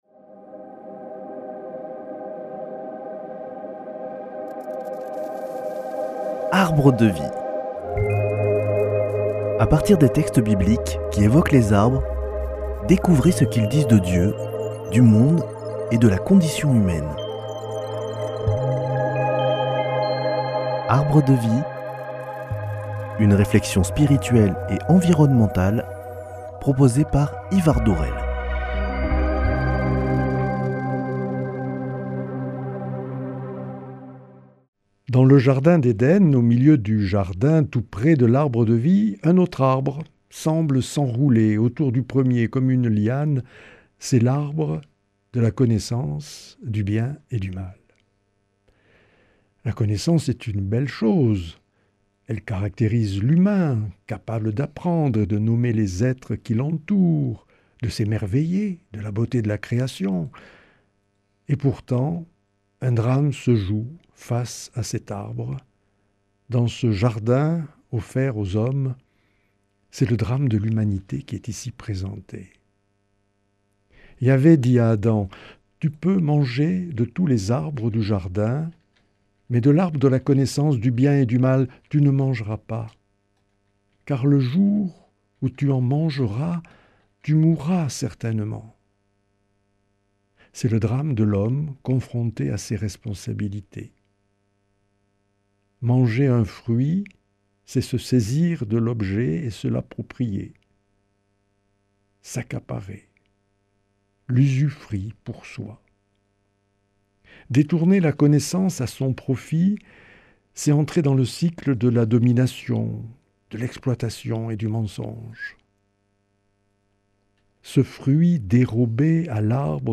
Au Jardin d’Eden, les humains sont face à leurs responsabilités. Comment prendre soin de la Création si on choisit la volonté de puissance et l’appropriation du bien commun ? Avec le botaniste Francis Hallé, trouvons dans la connaissance des arbres, la chance de s’émerveiller